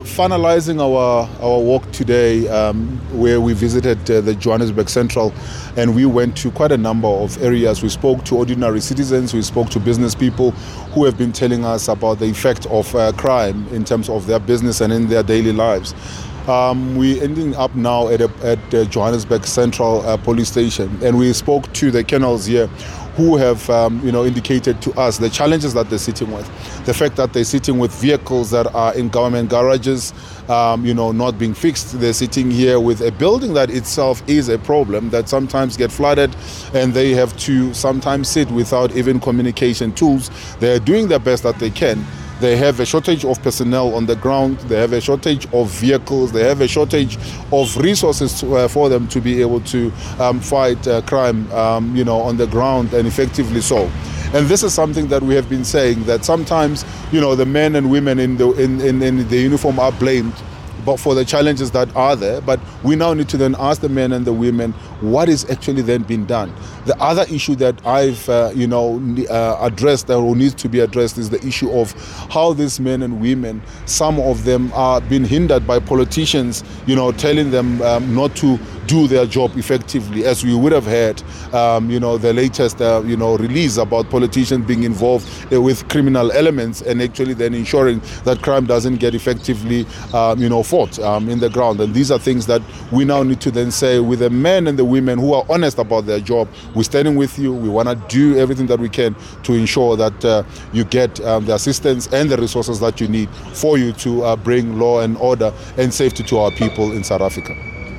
soundbite by Solly Msimanga MPL.